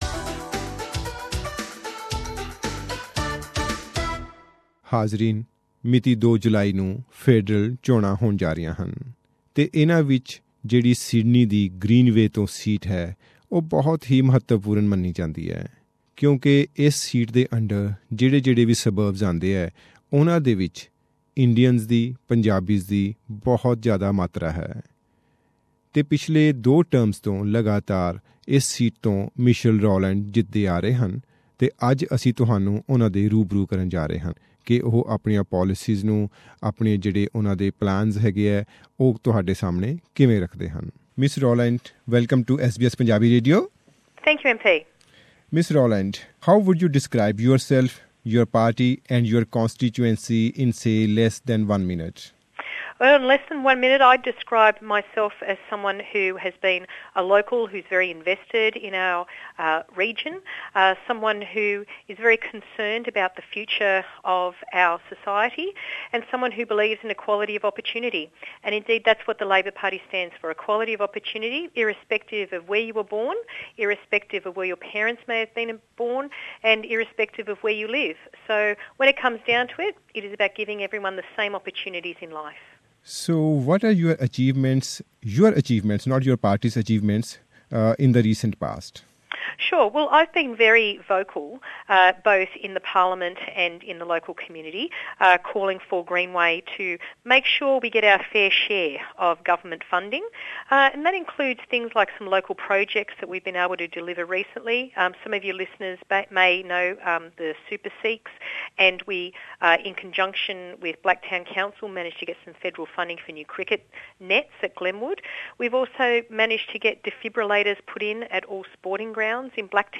Here is an interview with Ms Michelle Rowland (sitting MP) and labor candidate from Greenway in Sydney.